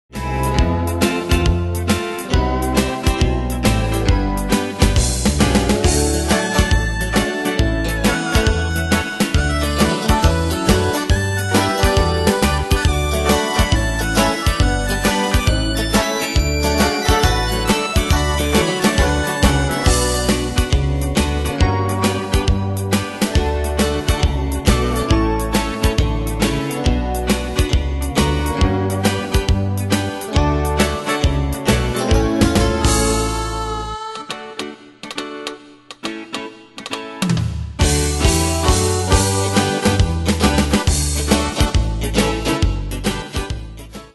Style: Country Année/Year: 1995 Tempo: 137 Durée/Time: 3.03
Danse/Dance: PopRock Cat Id.
Pro Backing Tracks